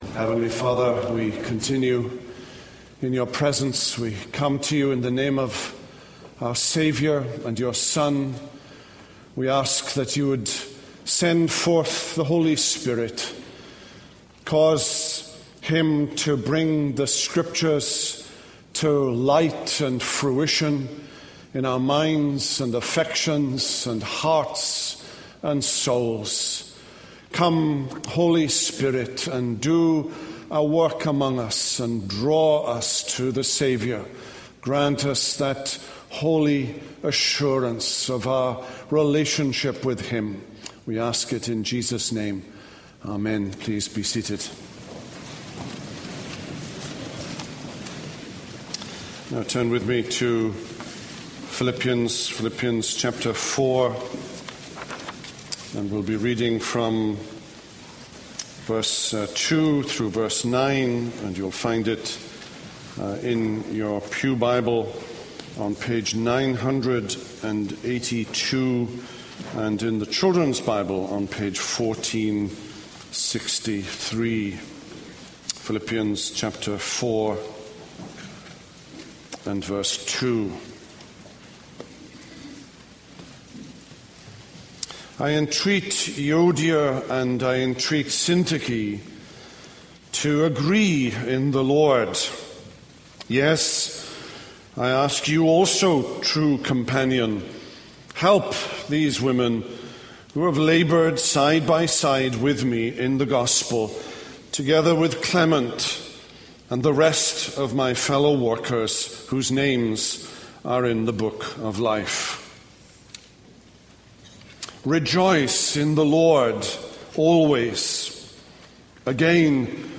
This is a sermon on Philippians 4:2-9.